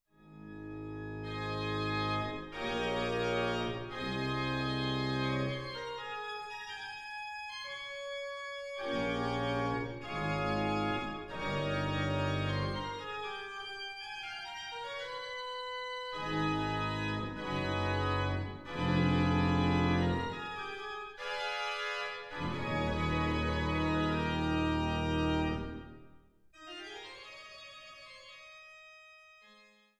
Orgel in Freiberg (beide Domorgeln), Helbigsdorf und Oederan